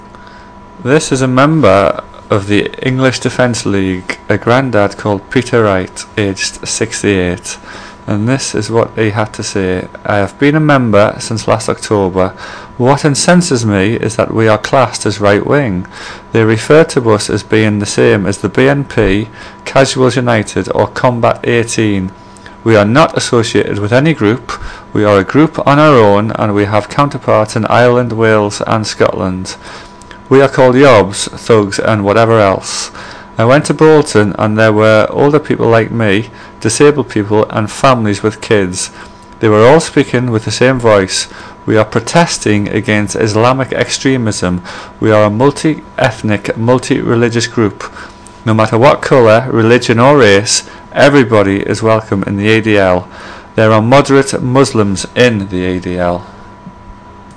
EDL member speaks